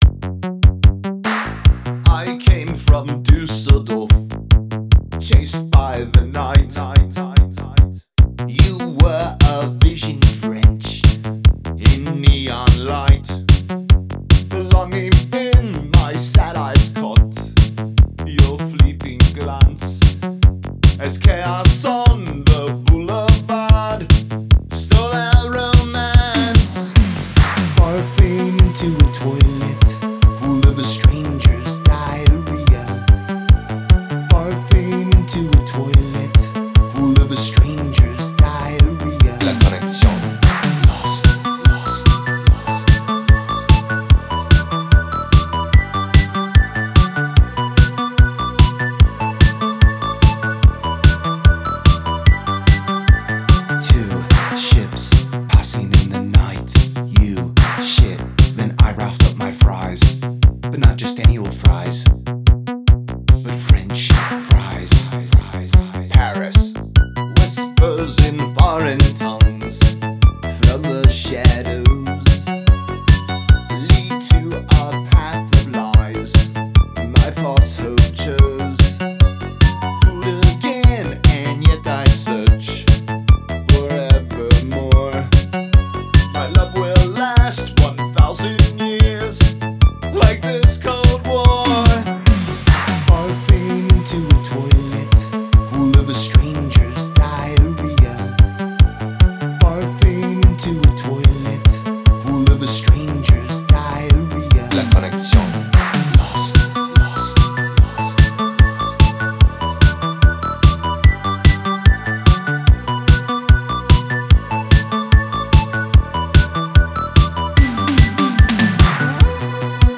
Жанр: Alternative, Rock